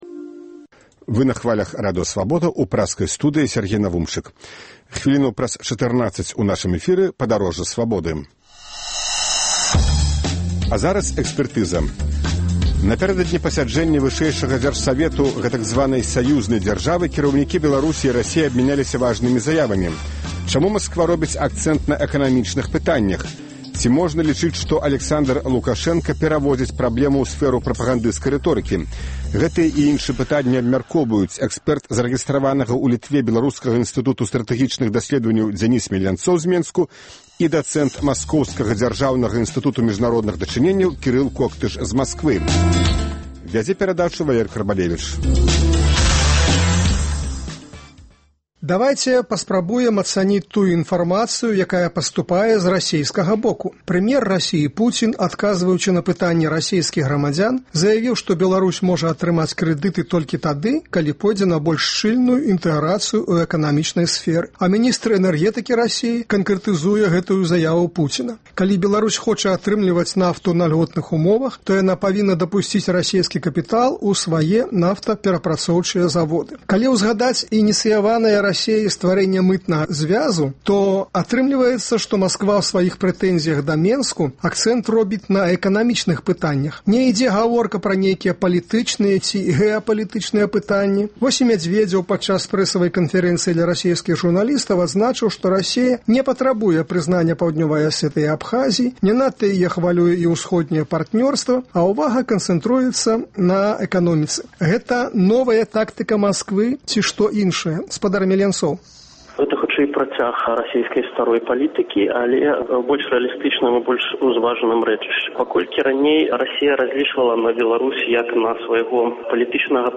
Гэтыя пытаньні абмяркоўваюць у “Экспэртызе “Свабоды”